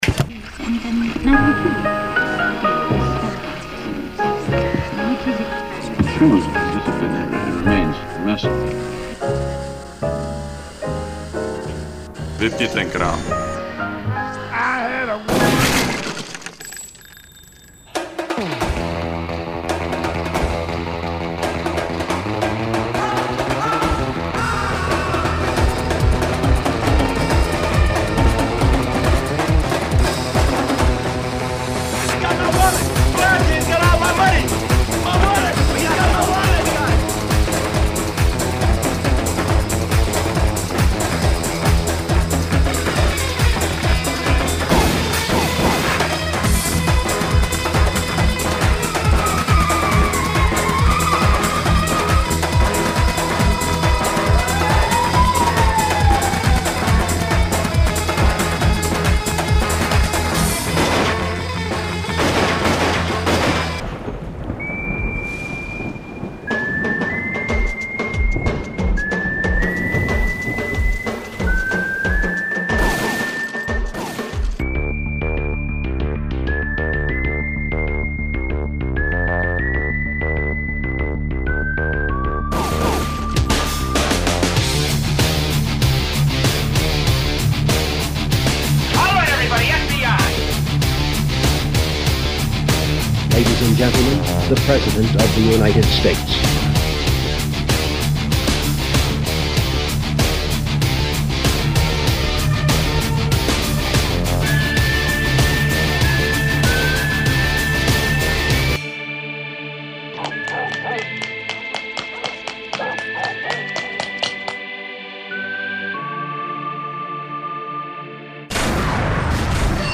Mluvené slovo, střelba
sirény, brzdy, ruchy z rádia.